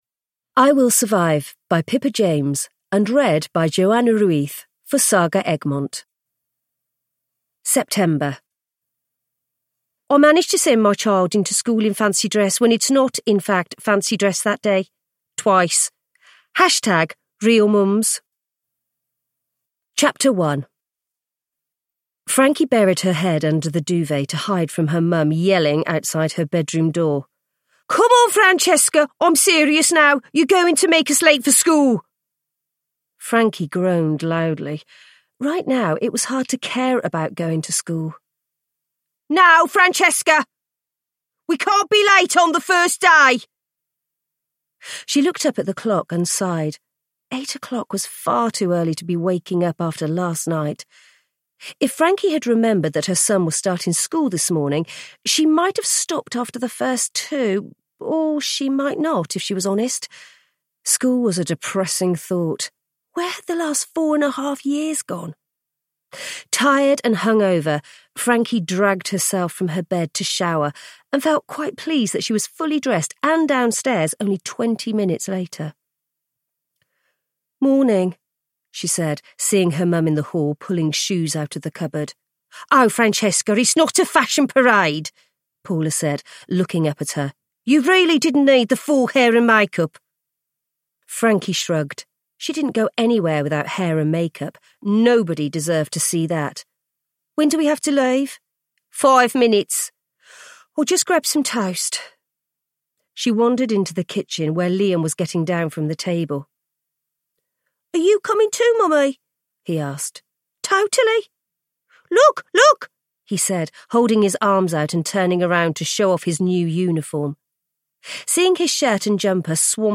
I Will Survive – Ljudbok